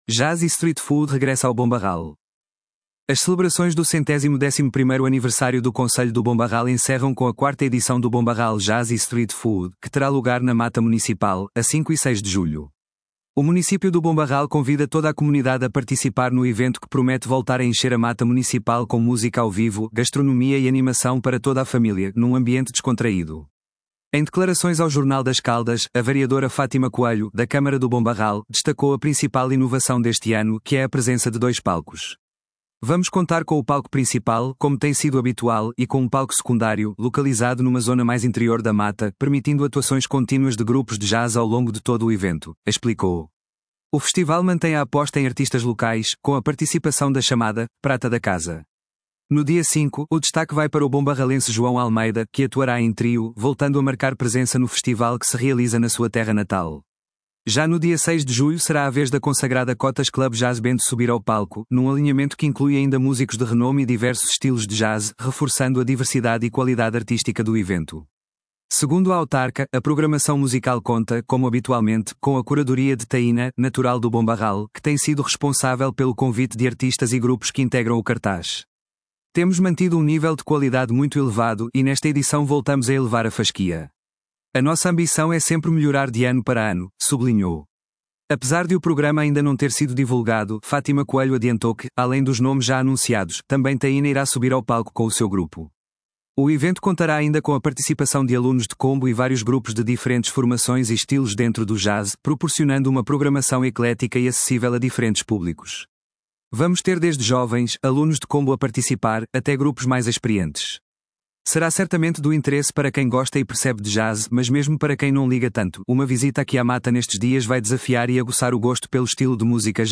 A vereadora Fátima Coelho destaca o evento com jazz ao vivo e uma oferta de street food